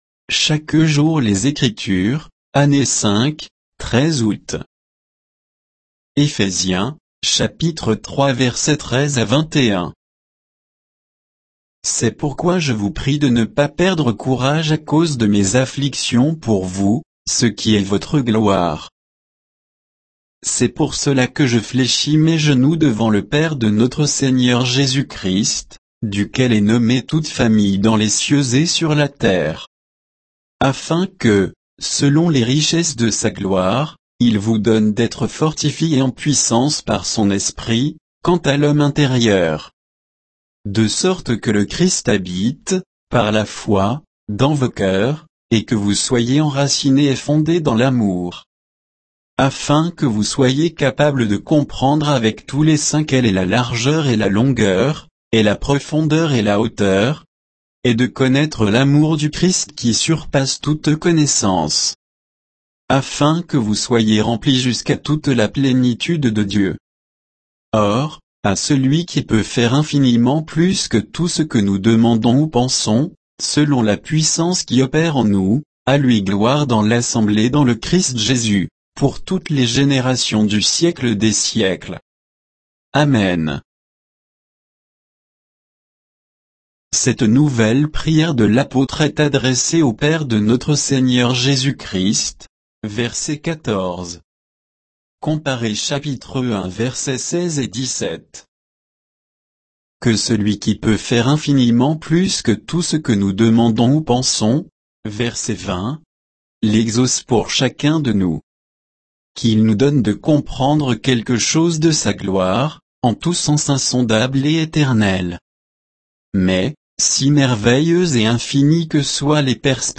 Méditation quoditienne de Chaque jour les Écritures sur Éphésiens 3, 13 à 21